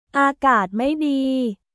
อากาศไม่ดี　アーガート・マイ・ディー